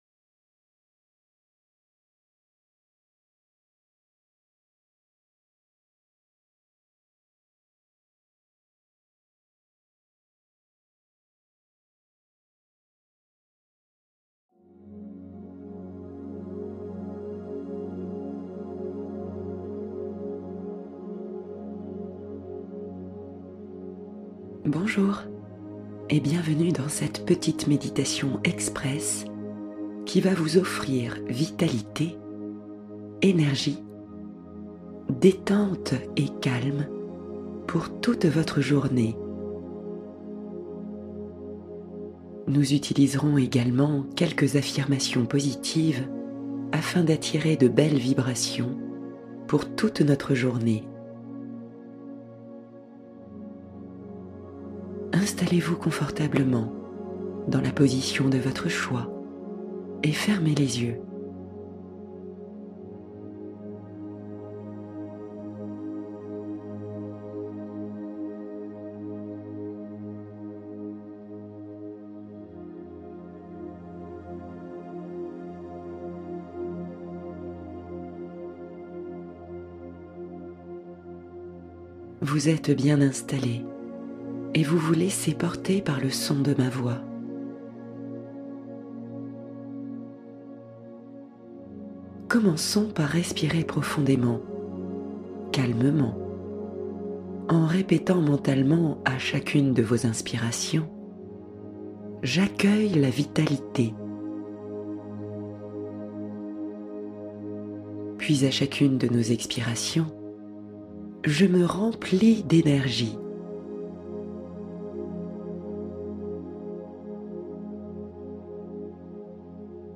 Guérir une partie de soi — Voyage guidé avec ho’oponopono